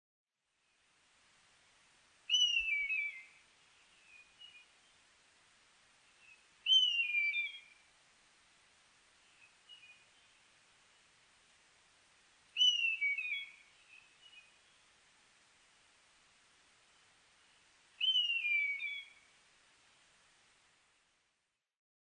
ノスリ　Buteo buteoタカ科
Mic.: audio-technica AT825